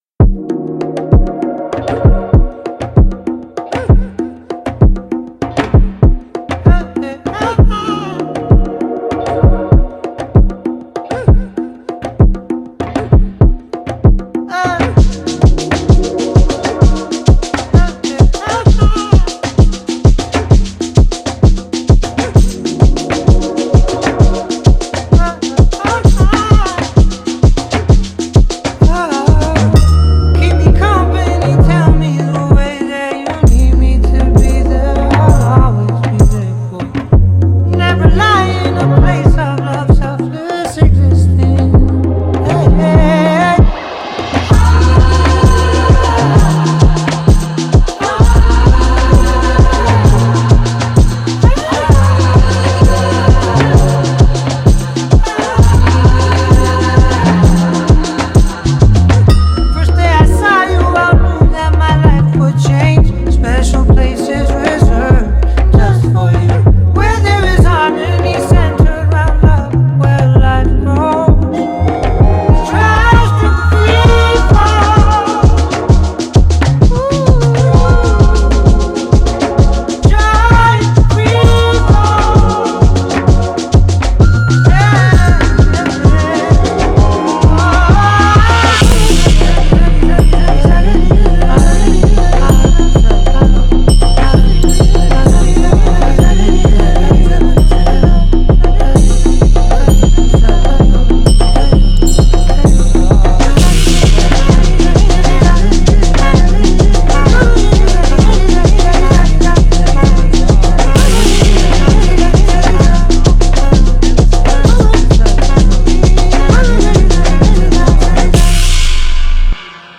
BPM130-130